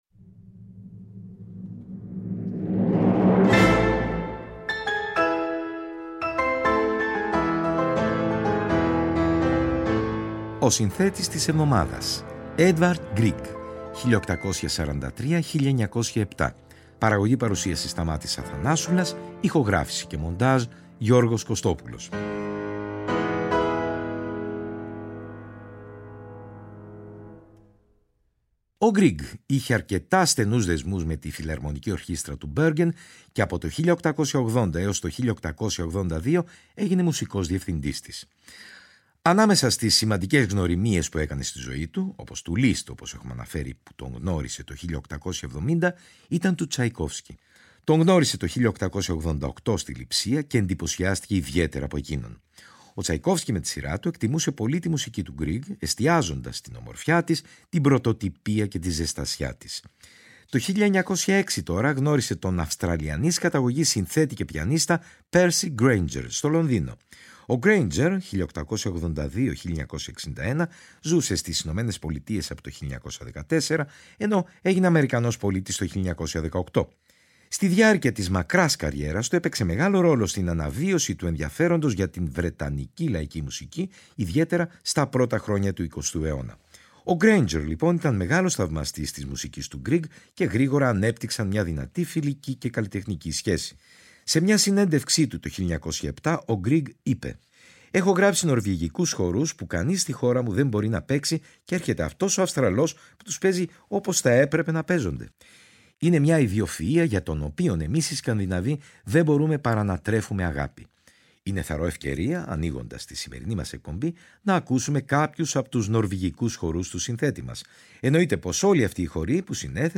Σε αυτό το αφιέρωμά μας, από την εκπομπή του Τρίτου Ο Συνθέτης της Εβδομάδας , θα ακουστούν κάποια από τα πλέον περίφημα έργα του.